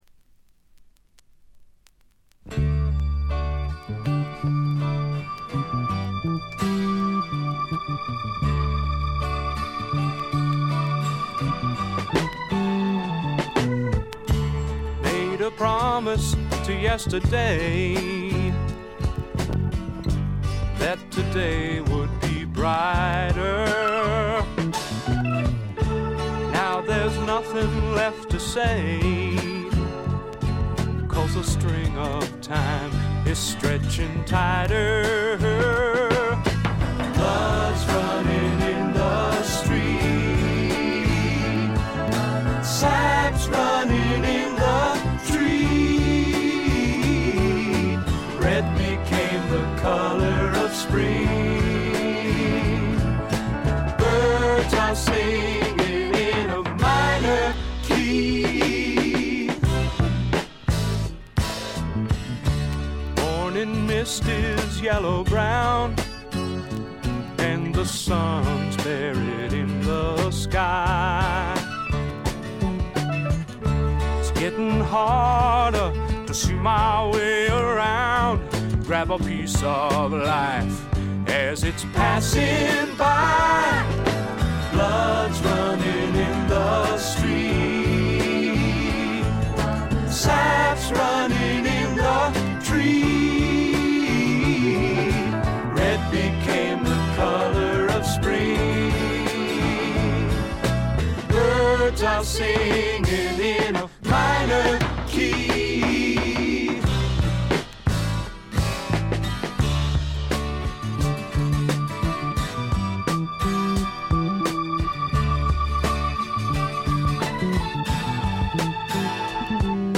ホーム > レコード：英国 SSW / フォークロック
静音部でチリプチが聞かれますが気になるノイズはありません。
試聴曲は現品からの取り込み音源です。